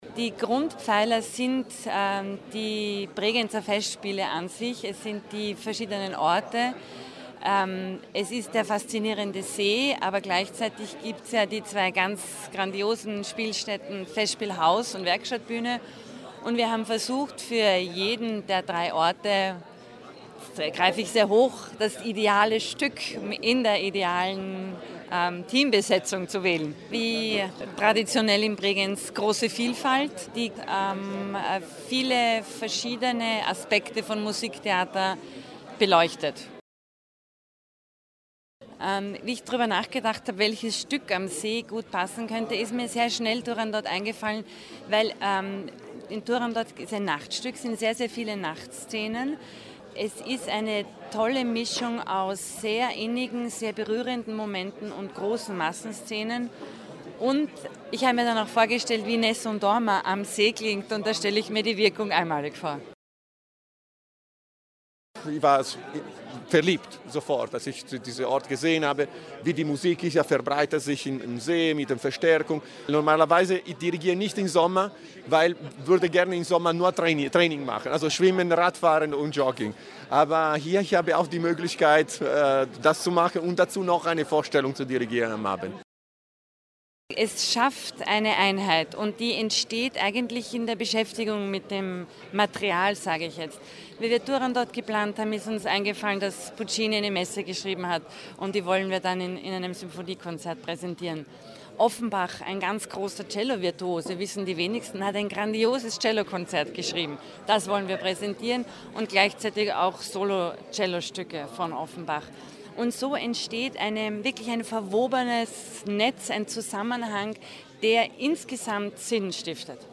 Programmpräsentation 2015 - O-Ton feature